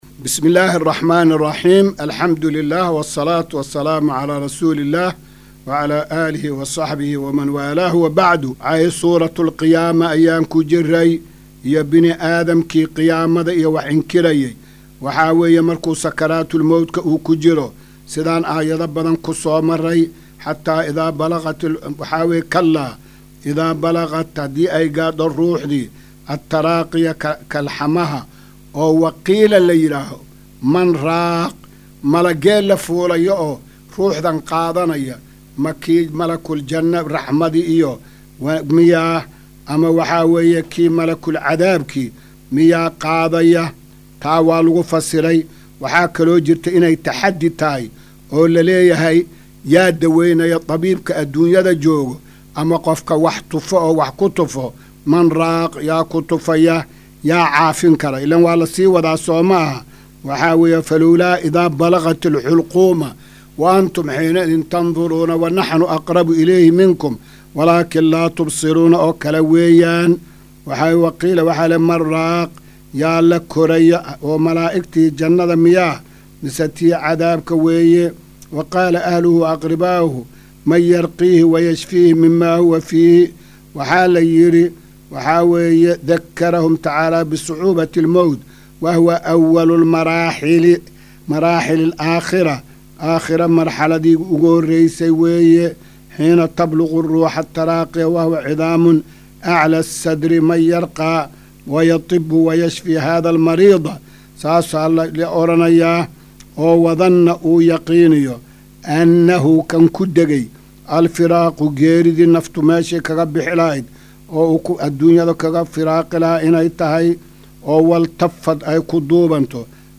Casharka-277aad-ee-Tafsiirka.mp3